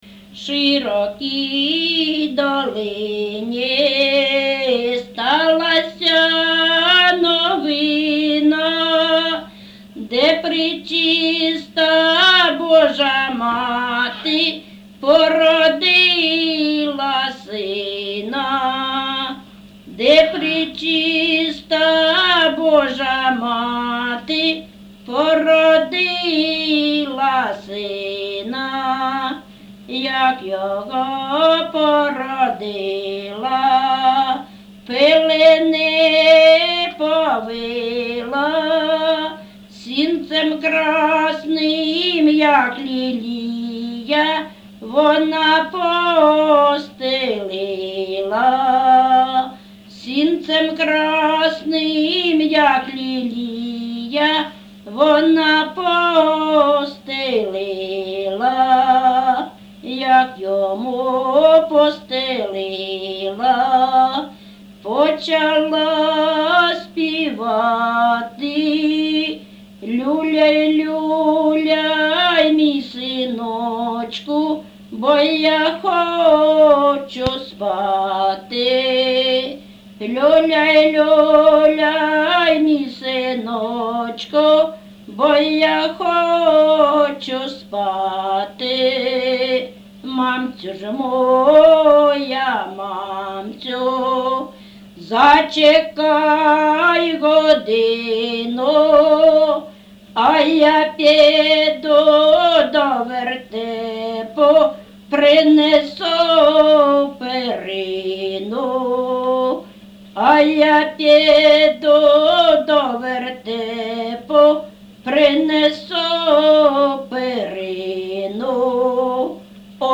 ЖанрКолядки
Місце записум. Старобільськ, Старобільський район, Луганська обл., Україна, Слобожанщина